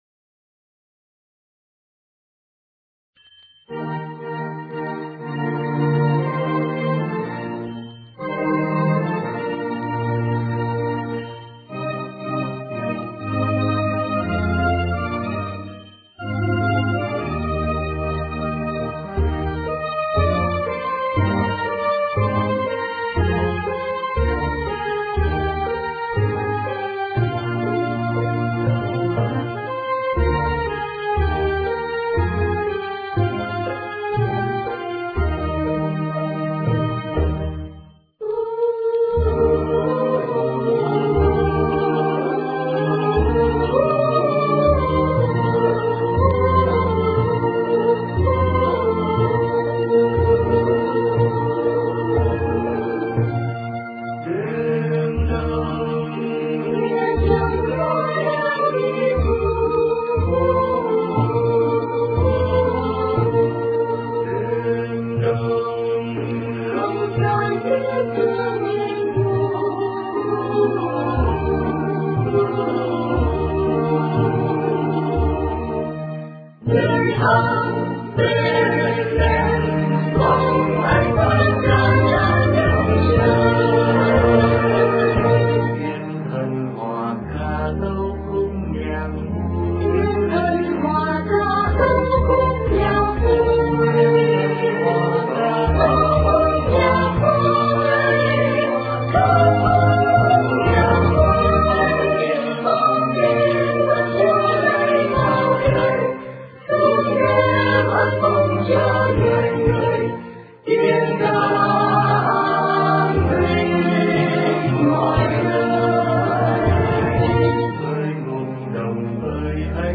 * Thể loại: Noel